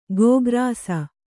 ♪ gōgrāsa